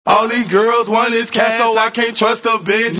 Tm8_Chant47.wav